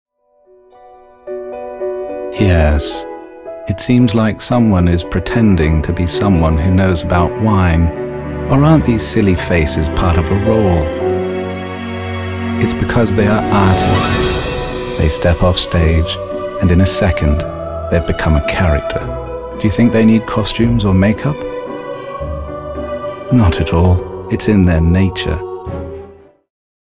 snide, sarcastic, cocky